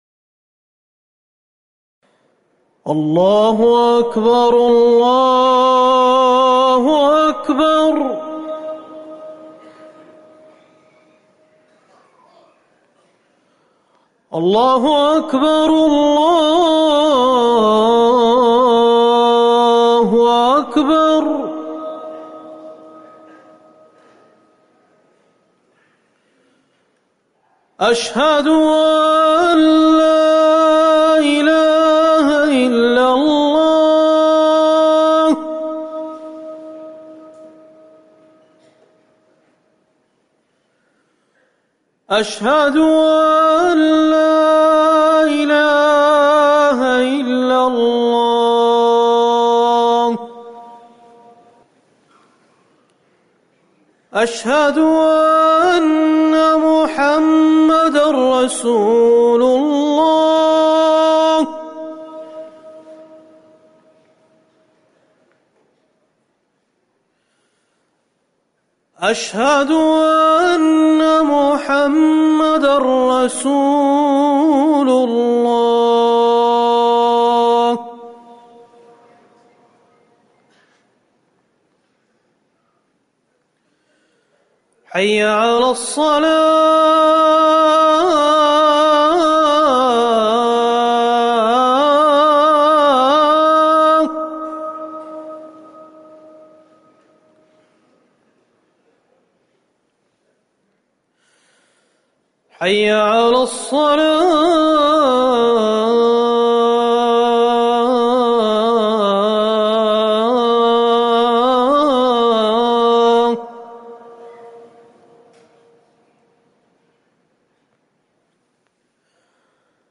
أذان الفجر الأول - الموقع الرسمي لرئاسة الشؤون الدينية بالمسجد النبوي والمسجد الحرام
تاريخ النشر ٢١ صفر ١٤٤١ هـ المكان: المسجد النبوي الشيخ